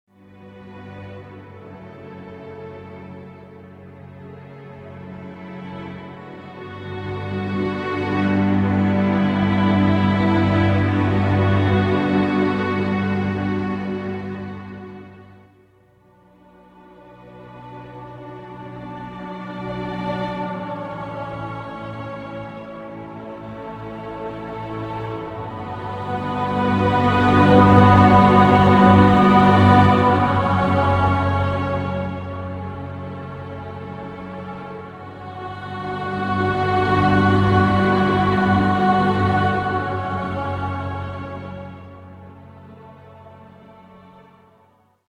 Tags: sad